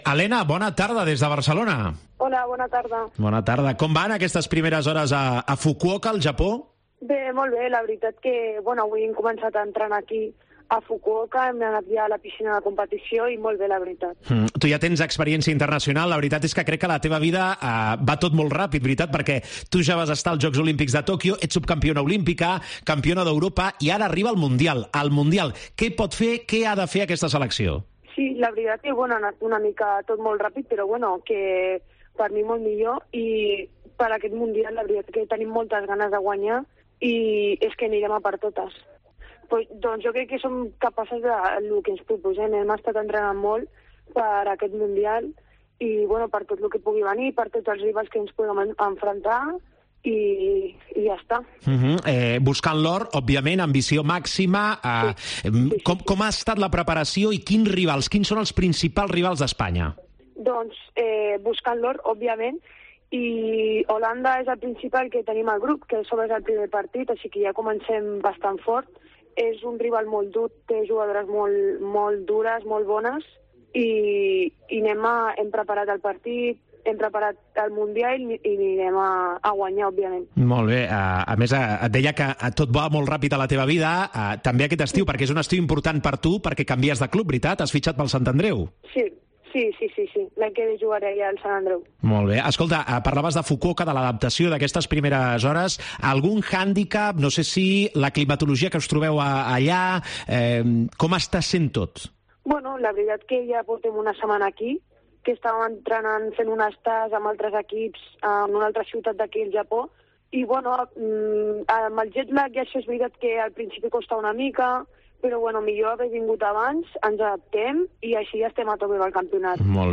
AUDIO: Aquest divendres comença a la ciutat japonesa de Fukuoka el Campionat del Món de Natació. Parlem amb la jugadora de waterpolo femení